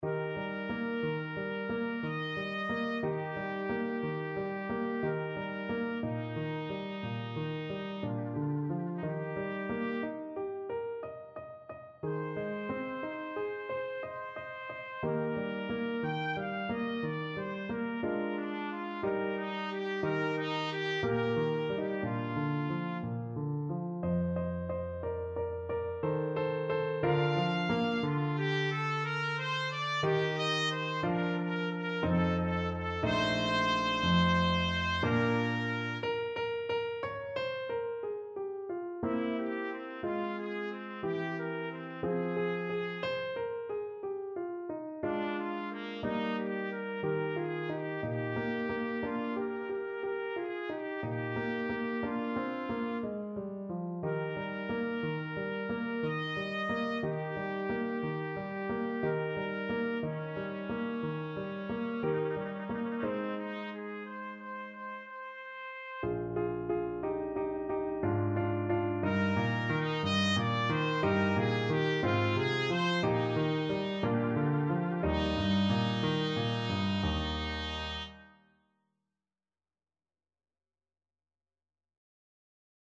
Trumpet
3/4 (View more 3/4 Music)
Bb4-G6
Eb major (Sounding Pitch) F major (Trumpet in Bb) (View more Eb major Music for Trumpet )
Andante =60
Classical (View more Classical Trumpet Music)
clementi_sonatina_2_op_36_TPT.mp3